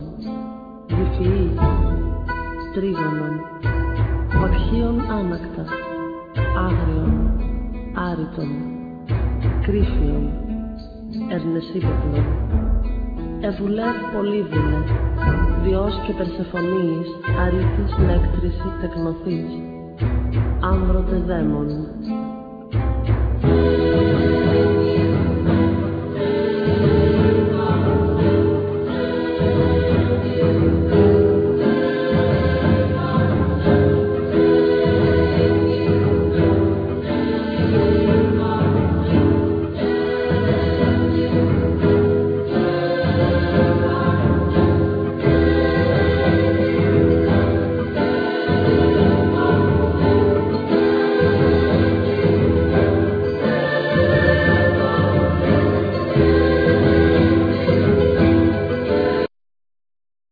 Ancient greek instruments